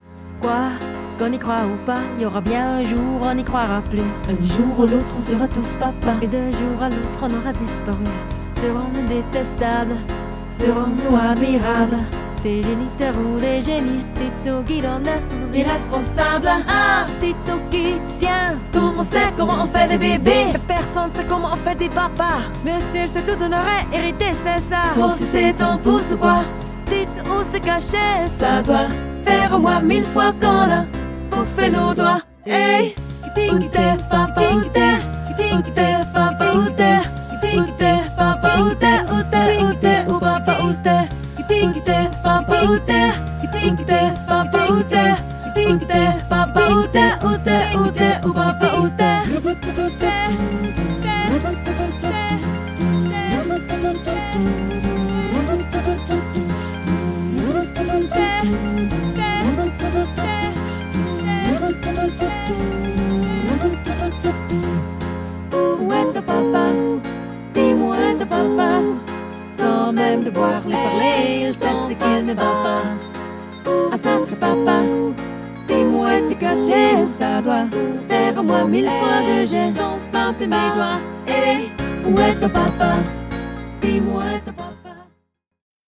Band
SSAA & Solo W